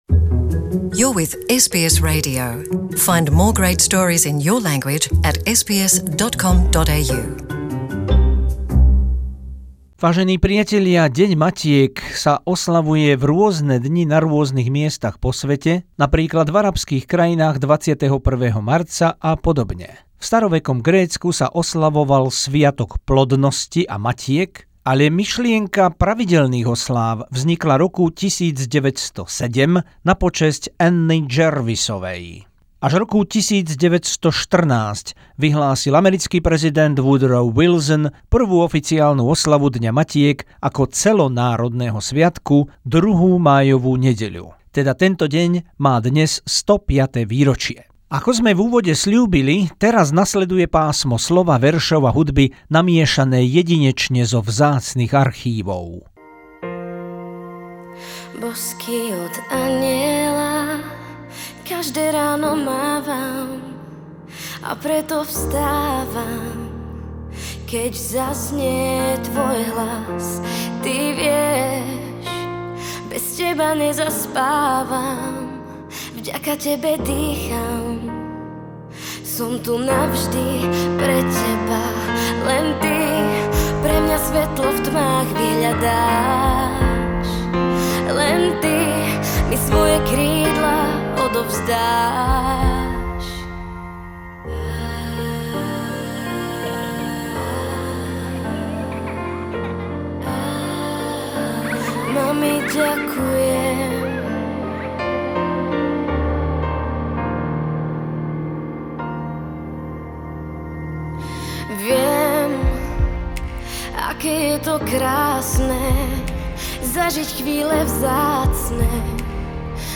Blok ku Dňu matiek s citom, veršami, piesňami a múdrym slovom od vzácnych ľudí